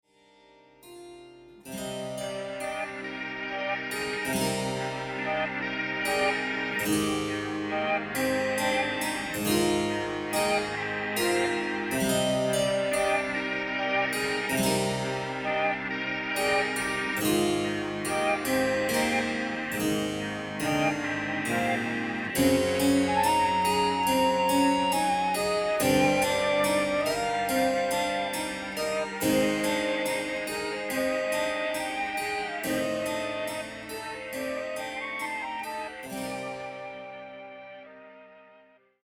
和洋折衷ジャズ＆ロック、